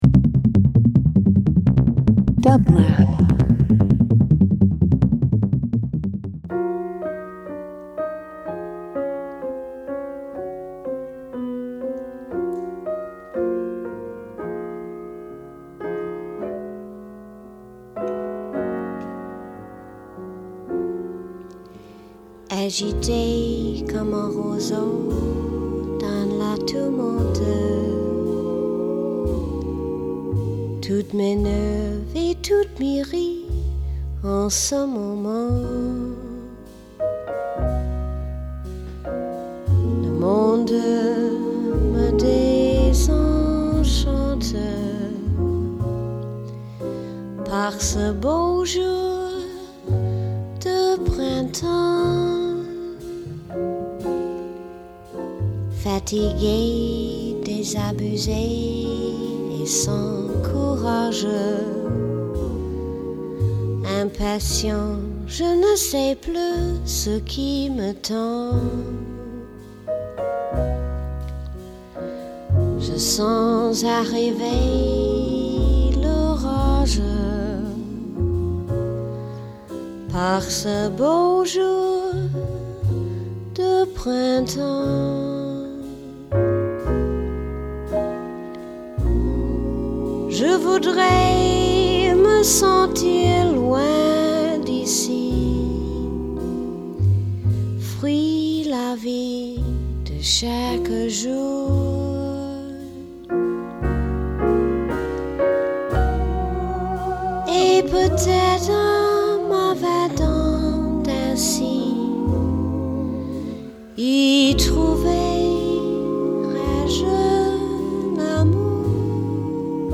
Jazz Voice